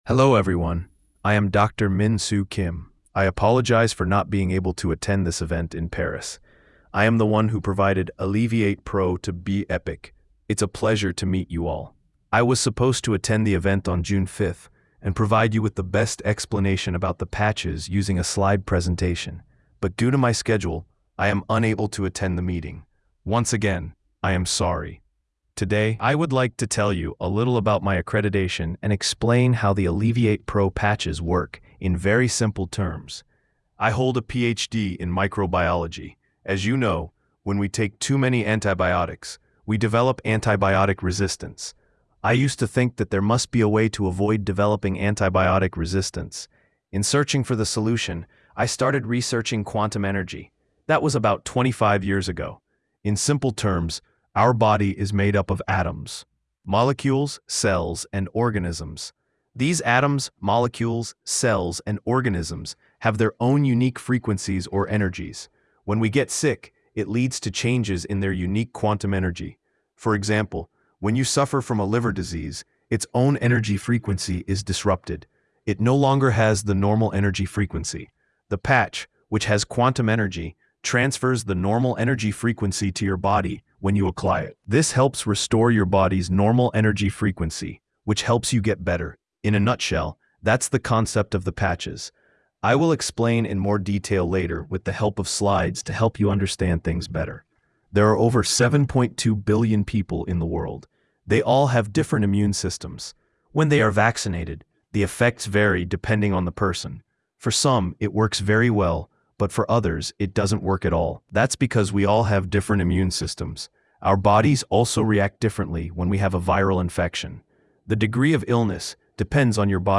TRANSLATIONS | Presentation